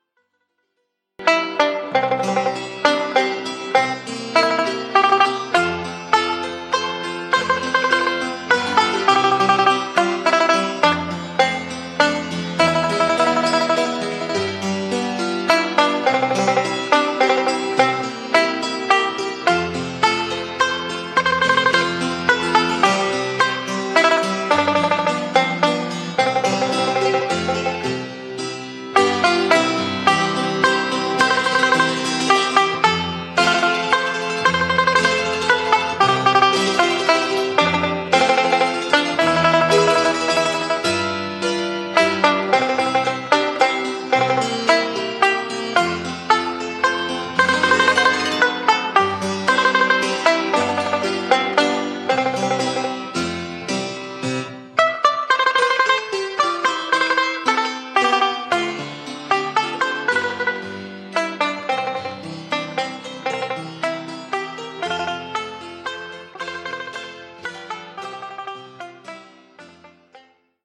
Traditional Irish air, first printed in a London songbook in 1775.
12-beat intro.
This Song is in 3/4 waltz time.